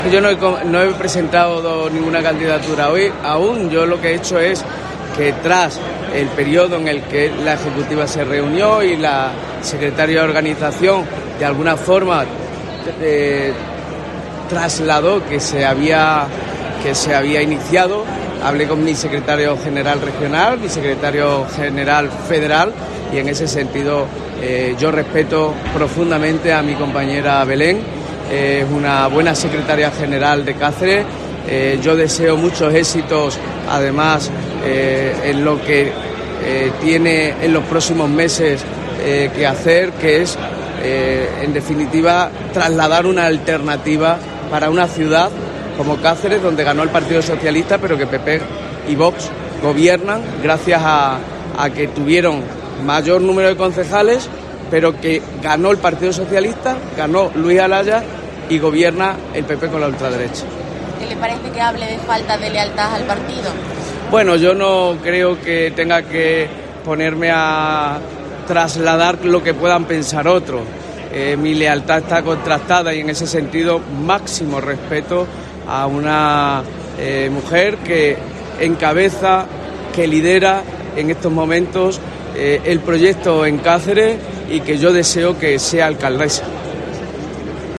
Gallardo, ante las preguntas de los medios en la inauguración de Expobarros, en Villafranca, afirmó que no ha presentado “ninguna candidatura aún” y que su lealtad “está contrastada”.